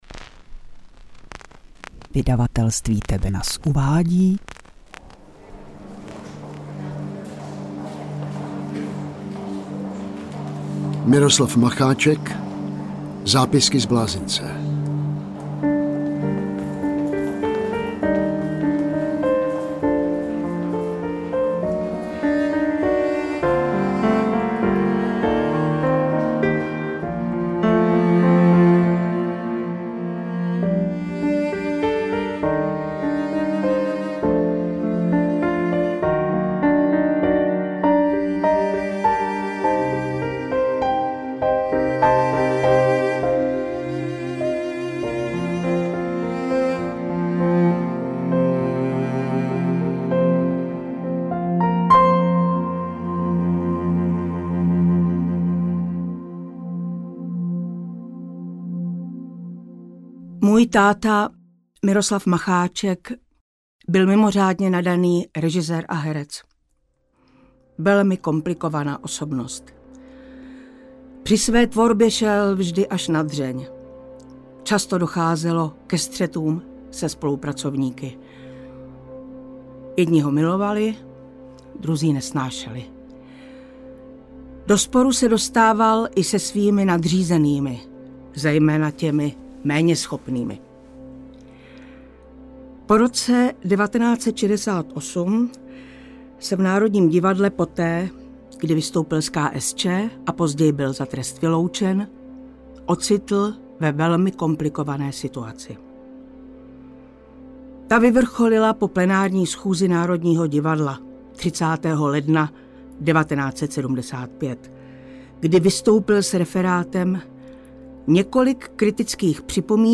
Interpret:  Jan Vlasák
Předmluvu napsala a načetla autorova dcera, herečka Kateřina Macháčková.
AudioKniha ke stažení, 73 x mp3, délka 6 hod. 2 min., velikost 493,6 MB, česky